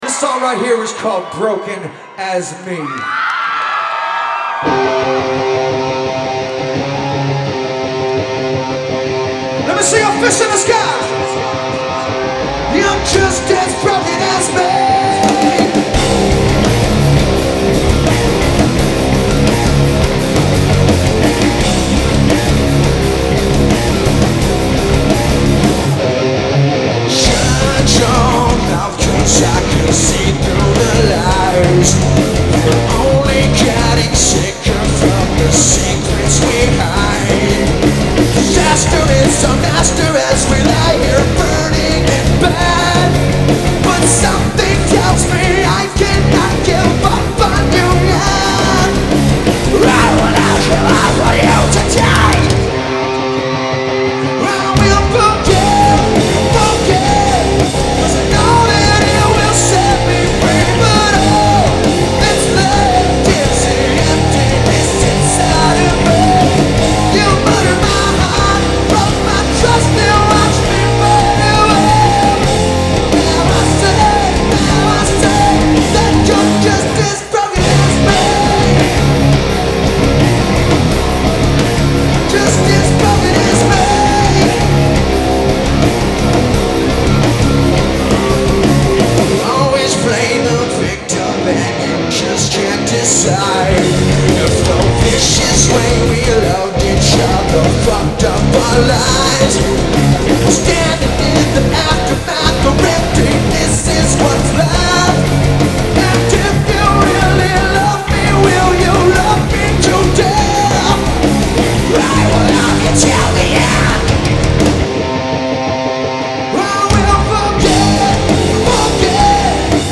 Genre : Alternative Rock
Live At Fillmore Detroit